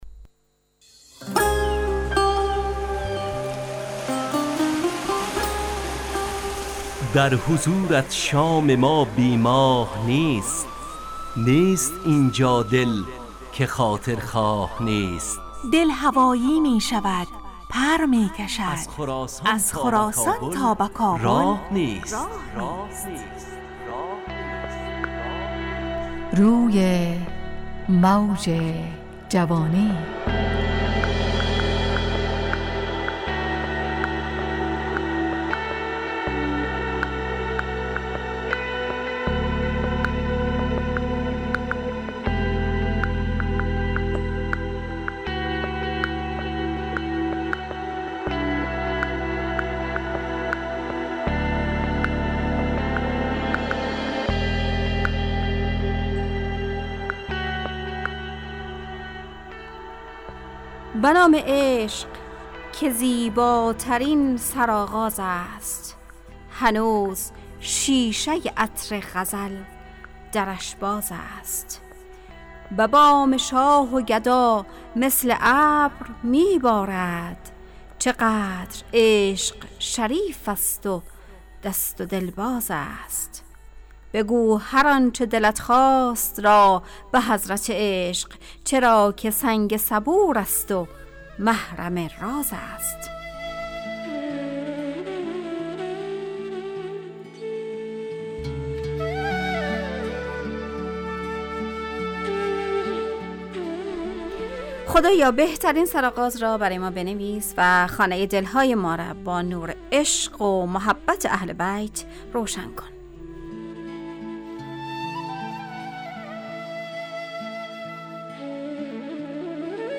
روی موج جوانی، برنامه شادو عصرانه رادیودری.
همراه با ترانه و موسیقی مدت برنامه 70 دقیقه . بحث محوری این هفته (سفر) تهیه کننده